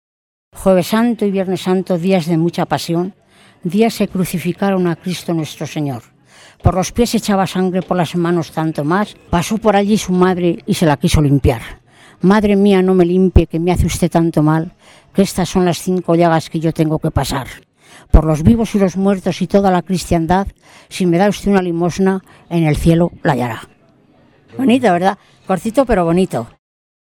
Clasificación: Romancero
Lugar y fecha de recogida: Lardero, 30 de abril de 2003